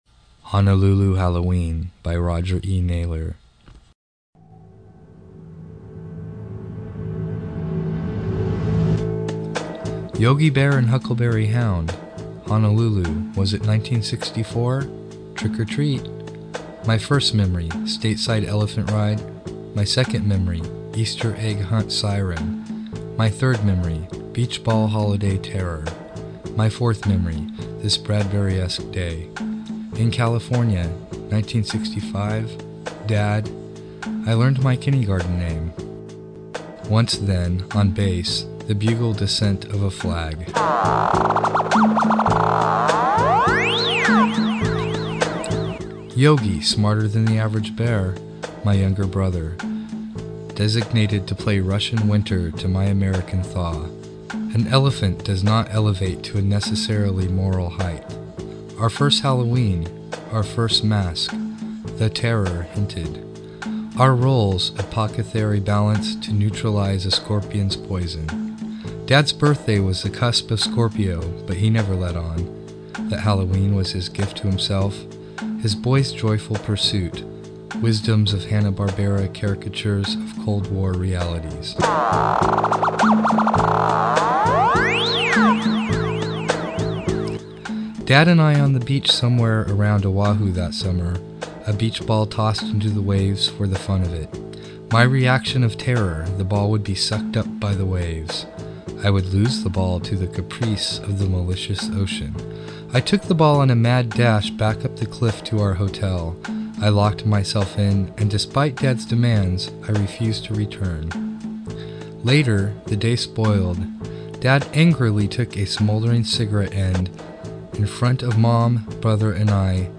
2006 Halloween Poetry Reading